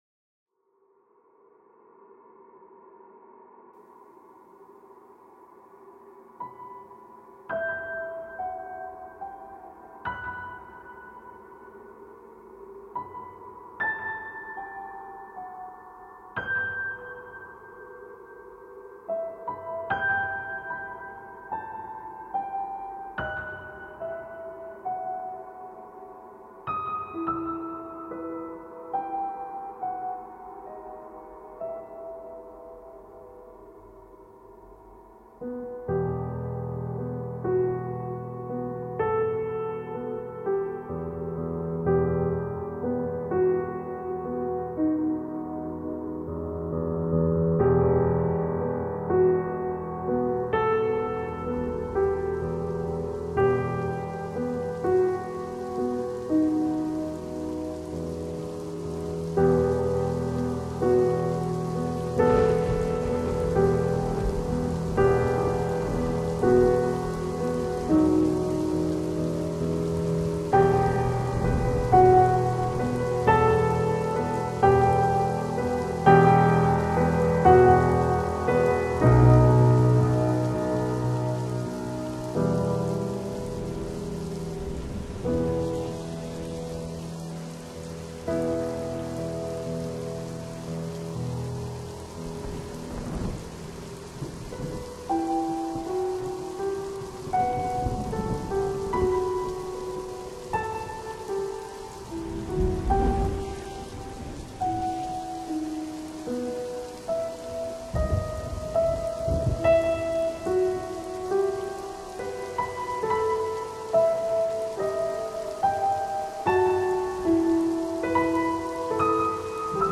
LAhw3b6mUja_dark-piano-sociopath-1-.mp3